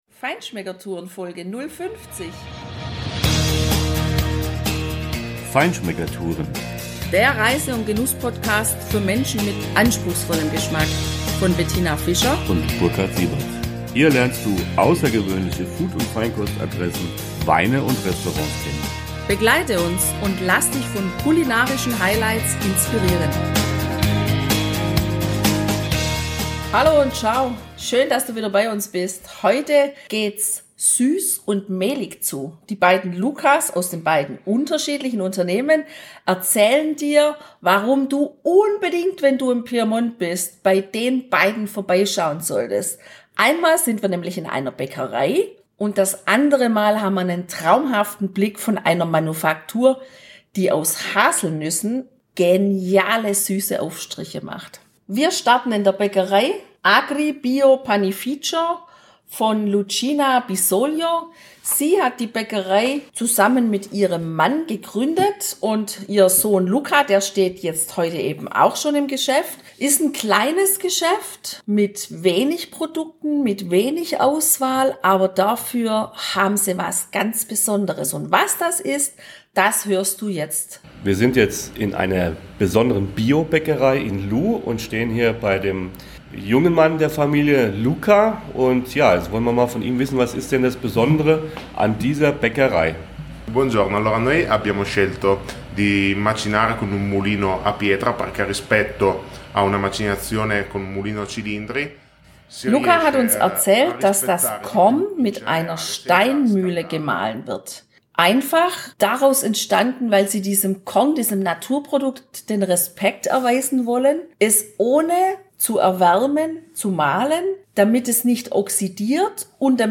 Wir sind in einer Bäckerei, die ihr Korn ganz frisch auf einer Steinmühle mahlt und vor allem von Allergikern sehr geschätzt wird.